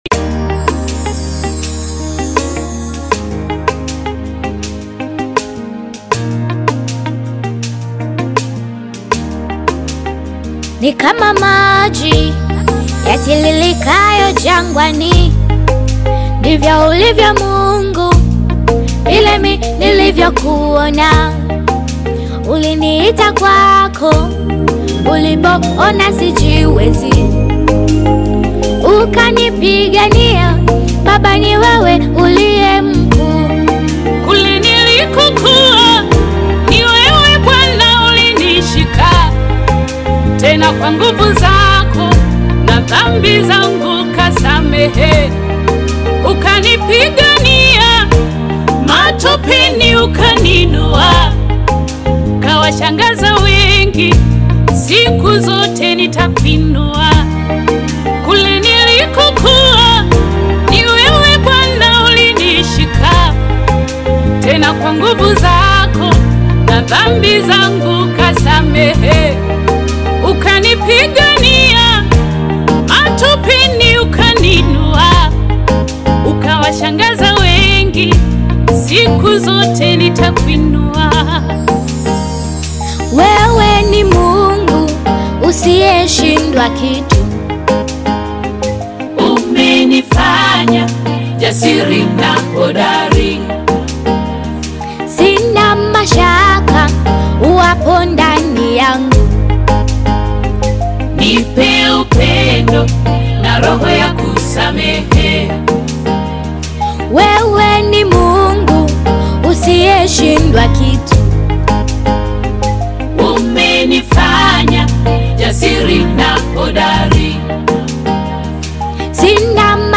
is a gospel track
Through their harmonious voices